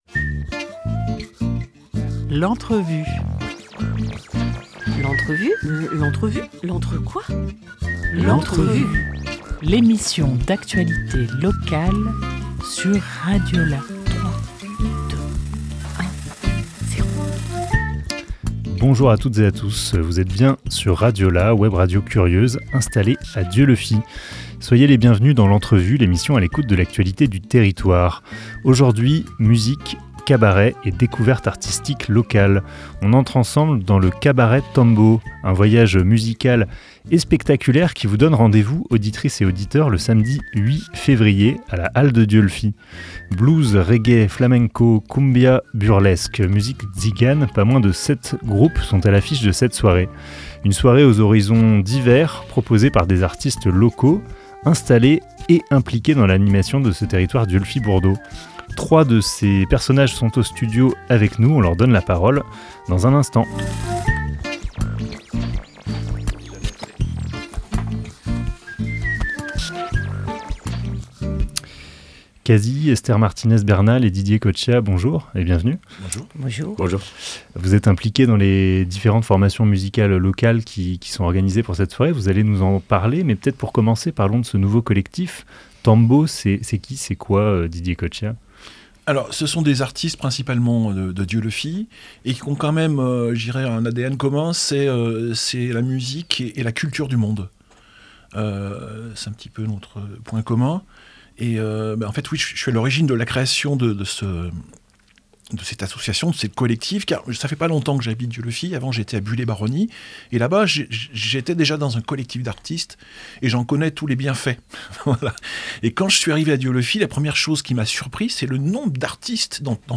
28 janvier 2025 11:34 | Interview